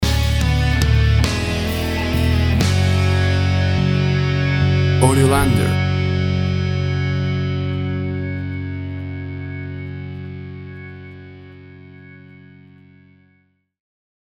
A big and powerful rocking version
Tempo (BPM) 130